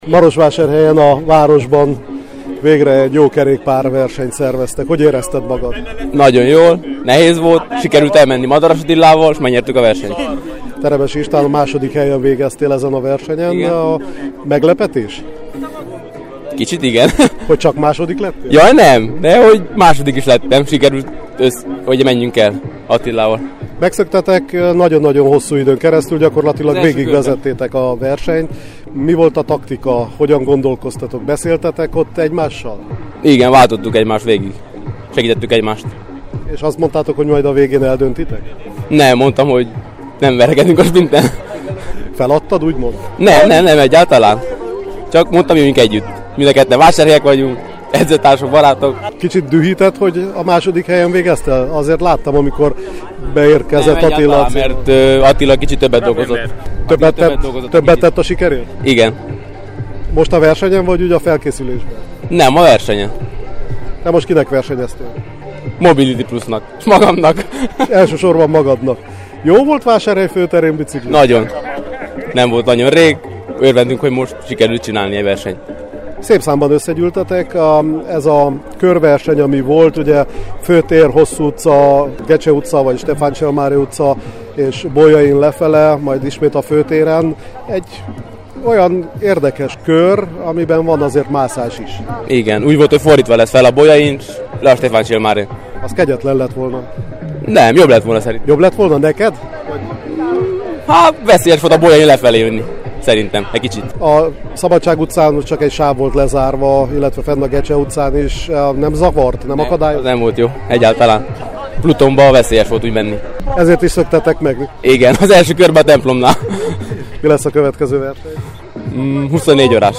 A verseny után az első két helyezett nyilatkozott a KISPAD-nak: